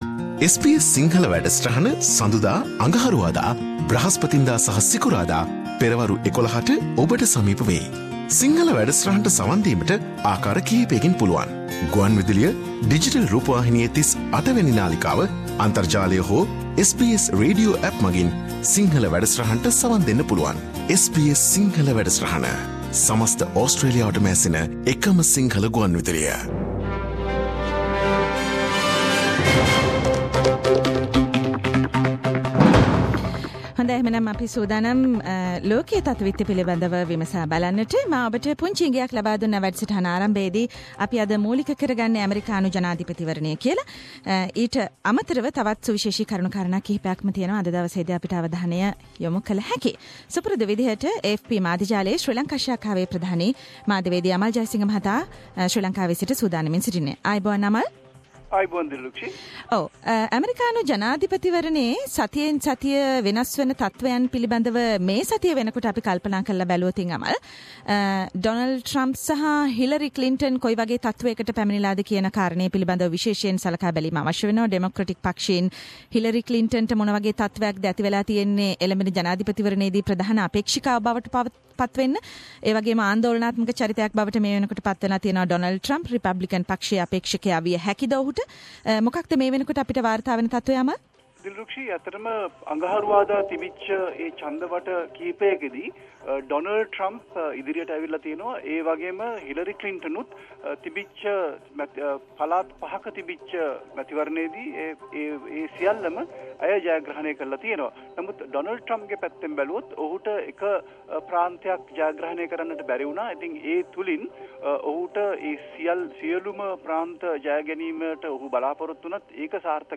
SBS Sinhala Around the World - Weekly World News highlights…..